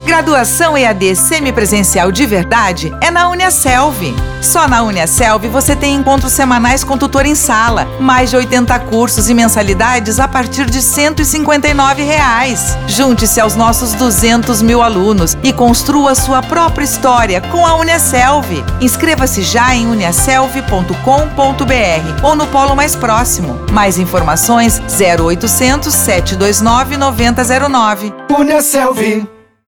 Feminino
Voz Varejo 00:29